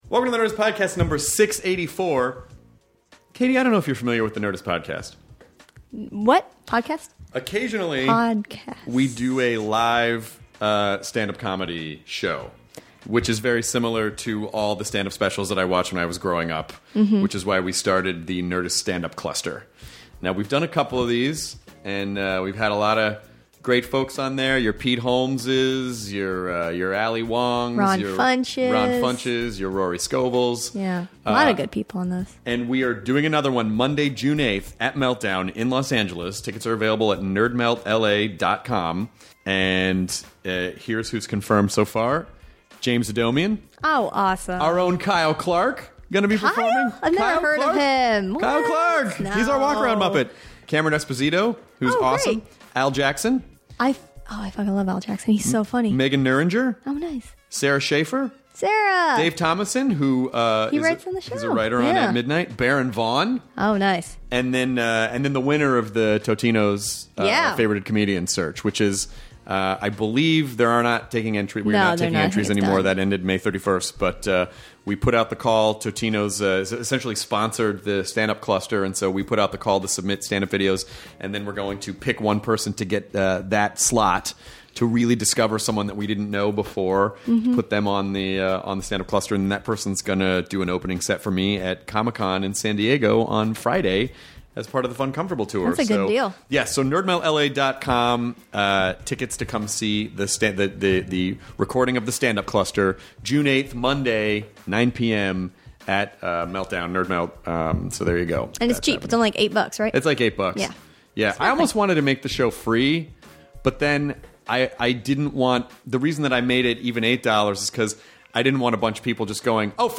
Mike Judge (Beavis and Butthead, Silicon Valley, Office Space) chats with Chris about going from the real Silicon Valley to making TV shows, what inspired him to get into animation, and his cartoons in the Spike and Mike Festivals! He also talks about where Cornholio came from, coming up with the story for Office Space, and casting everyone in Silicon Valley!